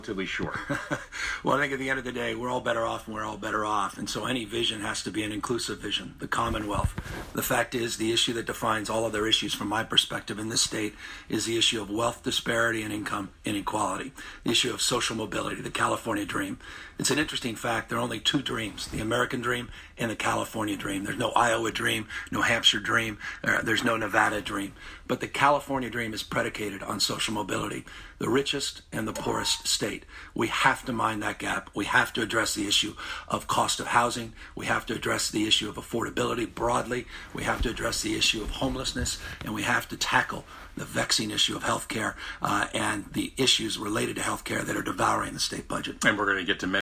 加州州长候选人民主党人纽森（Gavin Newsom）和共和党人考克斯（John Cox）8日上午10点在旧金山公共广播电台KQED展开辩论，解释为什么选民应该选他。
现任副州长的纽森对加州未来的期许：